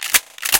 leverActionReload.ogg